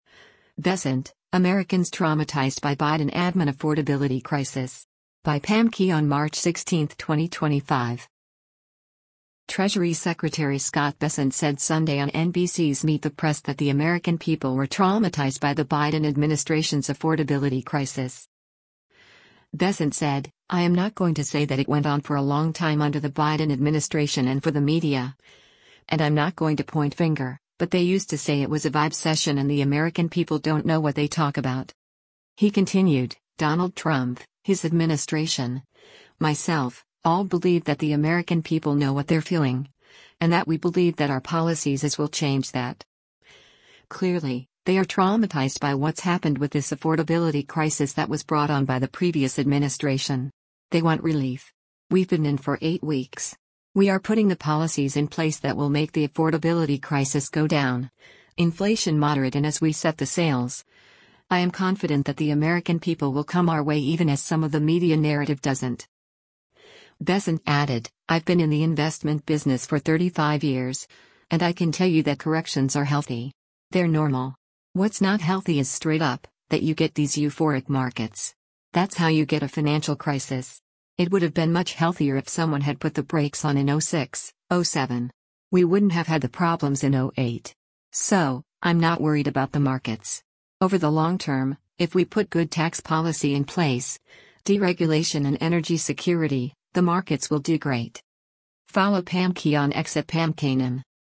Treasury Secretary Scott Bessent said Sunday on NBC’s “Meet the Press” that the American people were “traumatized” by the Biden administration’s “affordability crisis.”